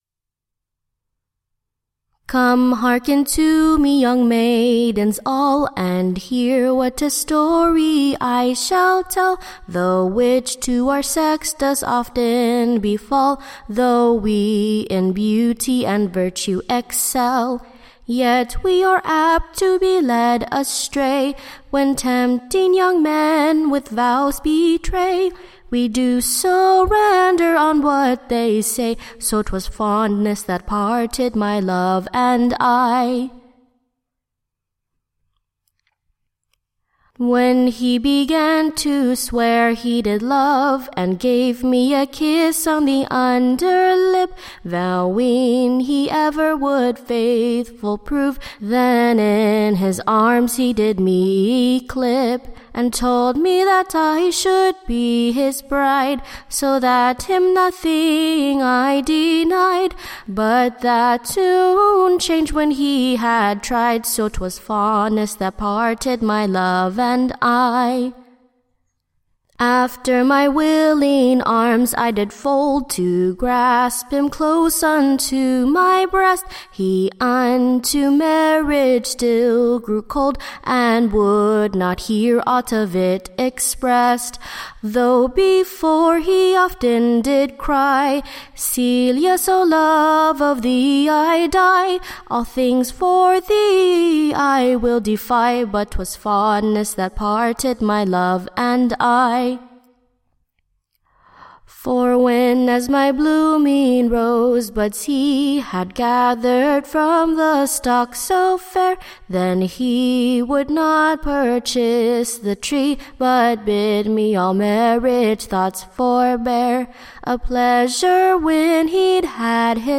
/ Being a Caution to the Female Sex. / Being a most pleasant new Song in two parts, With the Youngmans kind Answer.